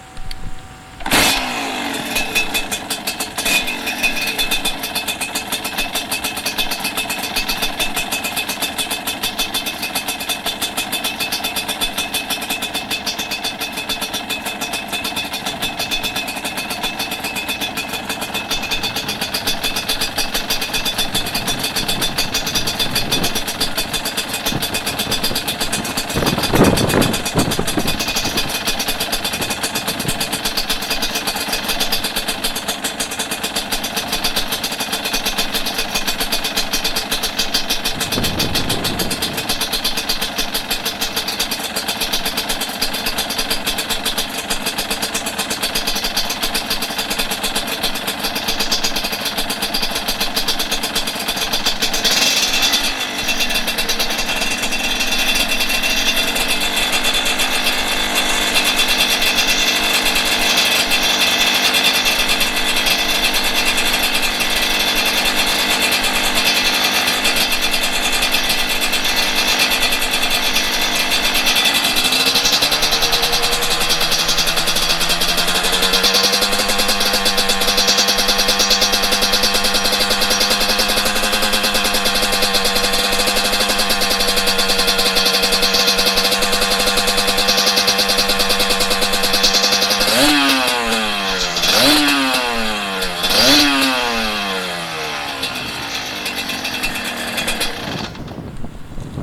測定位置は下写真の通り、サイレンサーのほぼ真横、2mくらい離れた位置にレコーダーをセットし、収録しました。
アイドリング → 3000rpm → 5000〜6000rpm → 8000〜9000rpmまでのブリッピングを3回 → 終了
なお、収録当日はあいにく風が強く、雑音がひどくて聞き苦しい箇所がありますがご容赦ください。
2. プレシャスファクトリーチャンバー＋R.S.V.サイレンサー／mp3形式／1分39秒
プレシャスファクトリーのチャンバーは、チャンバー内に消音装置を持たない1枚皮（？）構造のため、排気の圧力波がチャンバー内壁をたたく音（高音）が、良く言えばレーシーなんですが（＾＾；）、正直なところかなり耳につきます（-_-；）